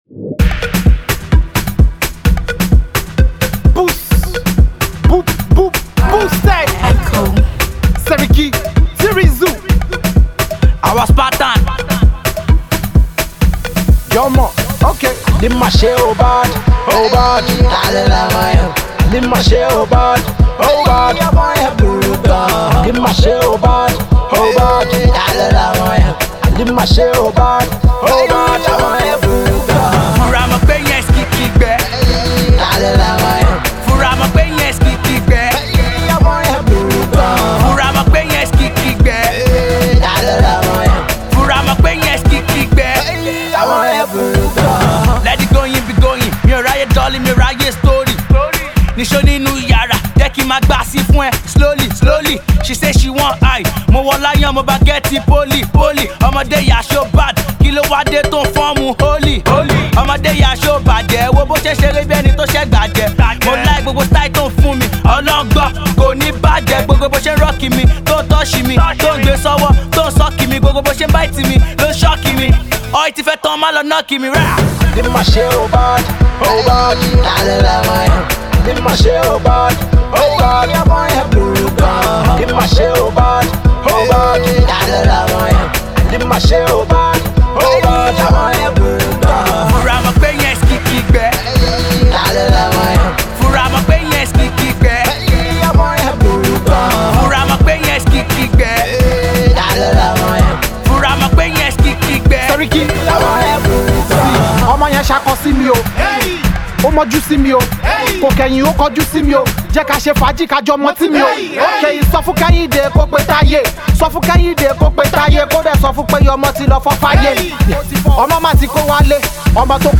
Alternative Pop
Afro Rapper